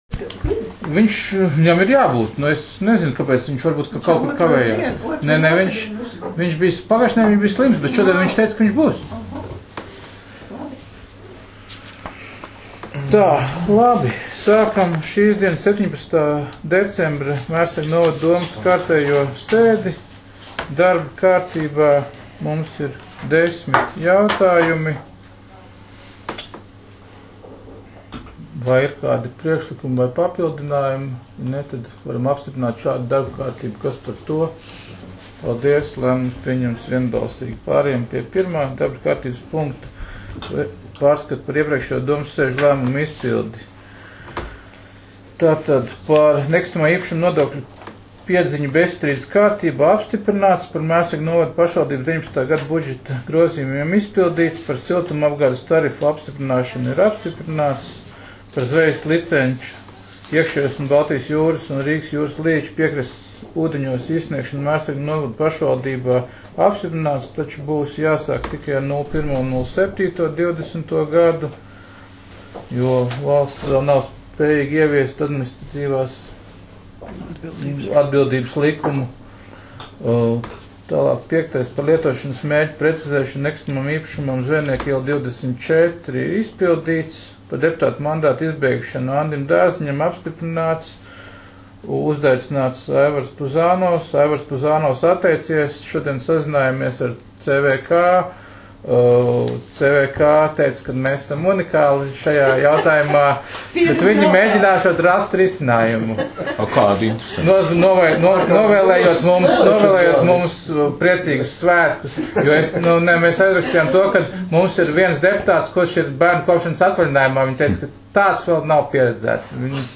Mērsraga novada domes sēde 17.12.2019.